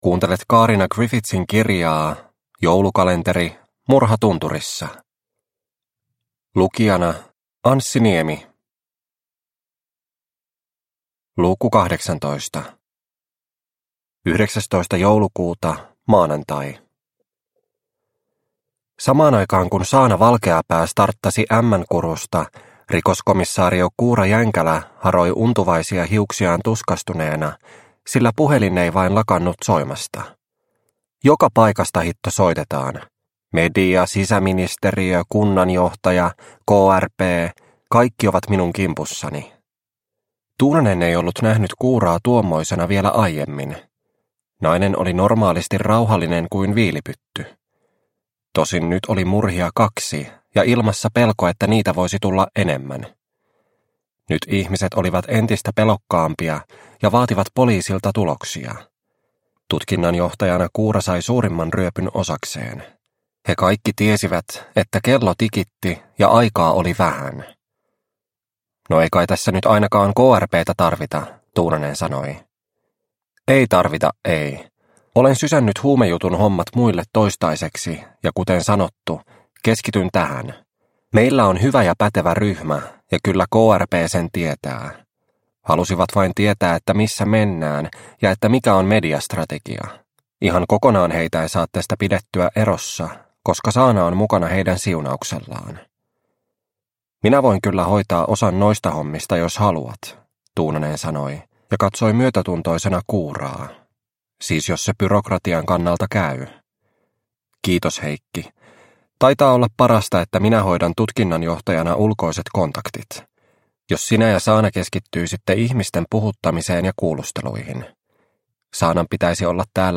Murha tunturissa - Osa 18 – Ljudbok – Laddas ner